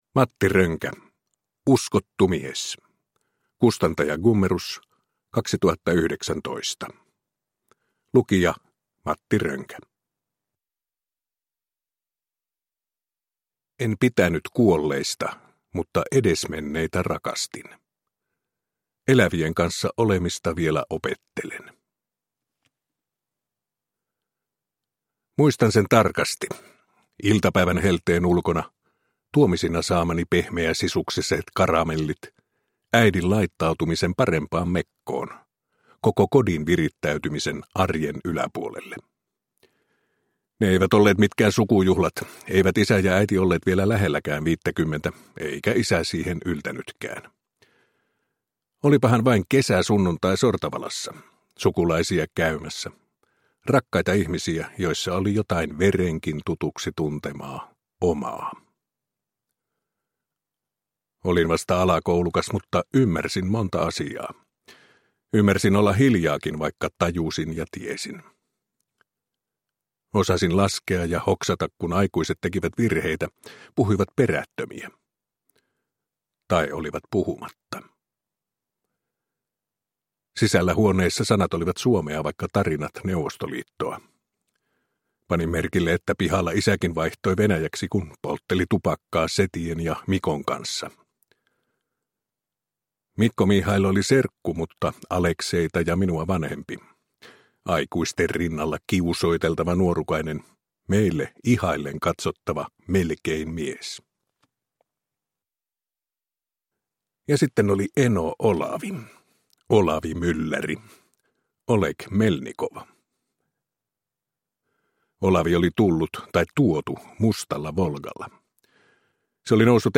Uskottu mies – Ljudbok – Laddas ner
Uppläsare: Matti Rönkä